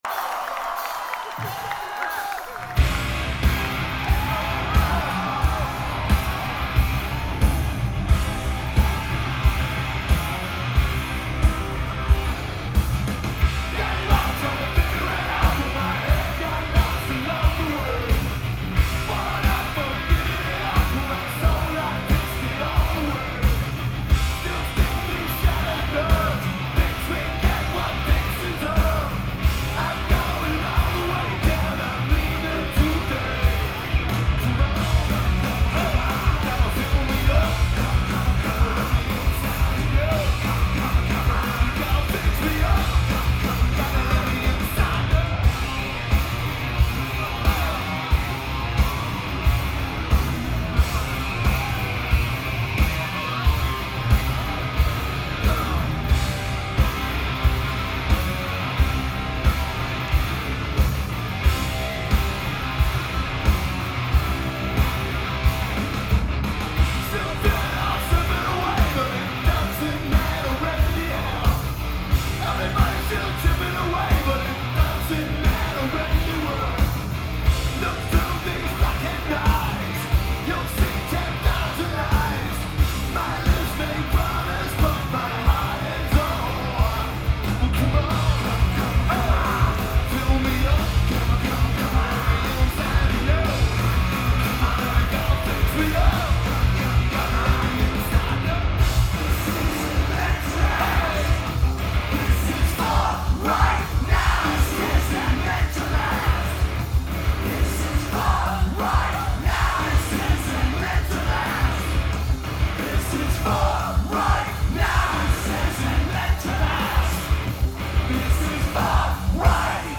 Terminal 5
Lineage: Audio - AUD (ATu853's + AT8532s + R09)